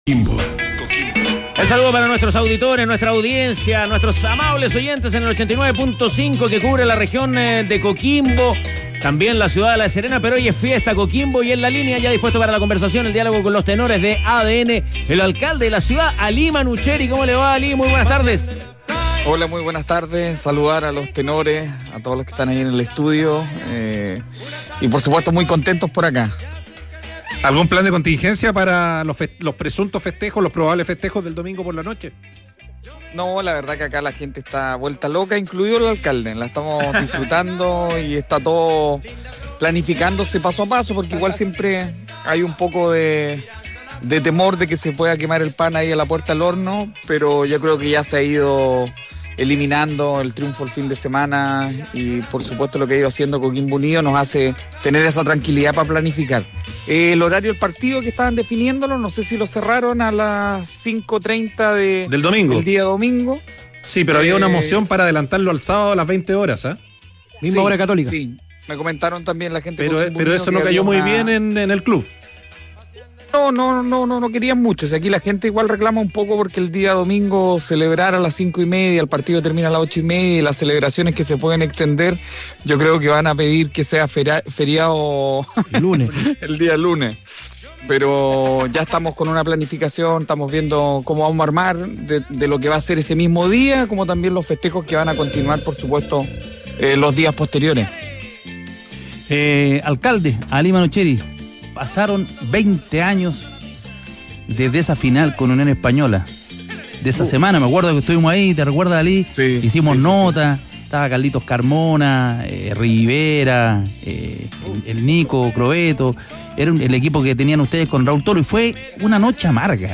En conversación con Los Tenores, esto planteó el líder comunal y excentral del elenco aurinegro, Alí Manouchehri.